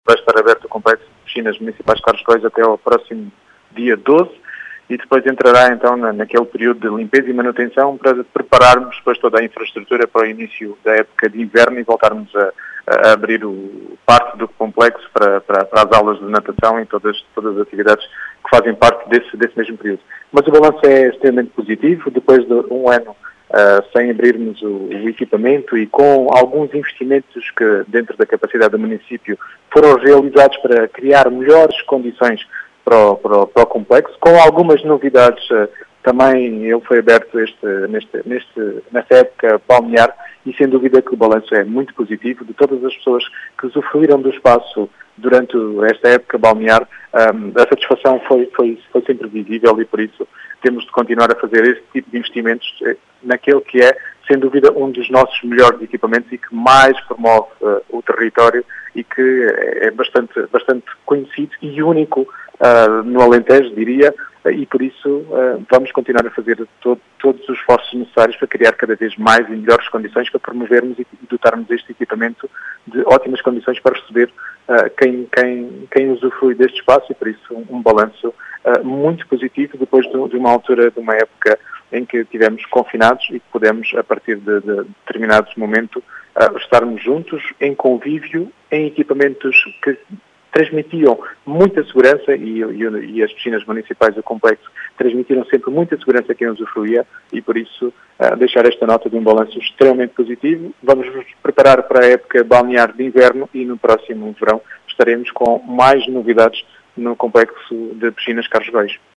As explicações são de Rui Raposo, presidente da Cãmara Municipal de Vidigueira, que fez um “balanço muito positivo” desta época balnear naquele equipamento, que é uma referência na região, e que transmitiu sempre “muita segurança”.